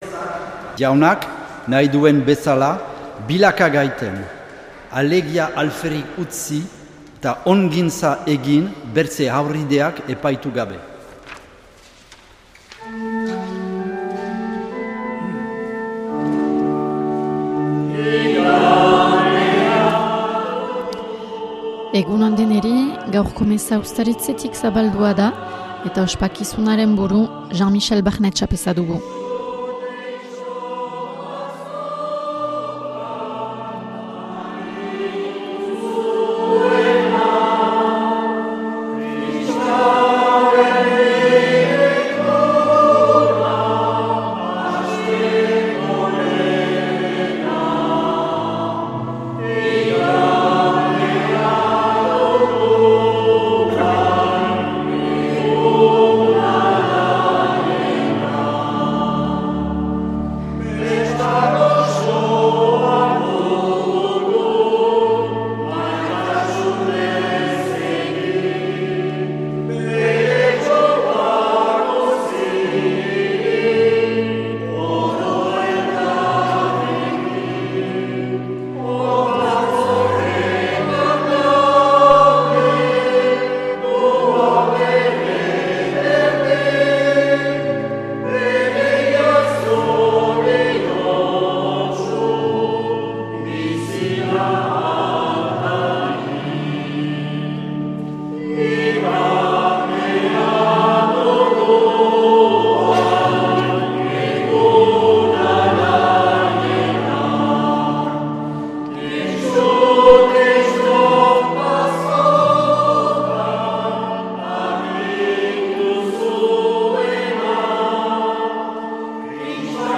Accueil \ Emissions \ Vie de l’Eglise \ Célébrer \ Igandetako Mezak Euskal irratietan \ 2025-03-02 Urteko 8.